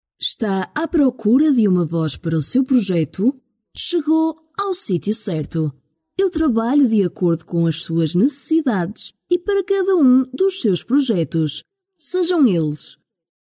葡萄牙语样音试听下载
葡萄牙语配音员（女4）